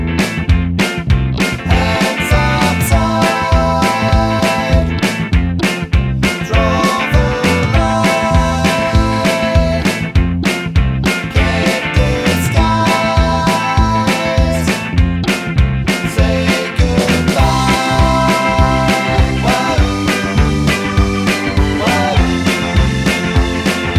no Backing Vocals Pop (2000s) 2:19 Buy £1.50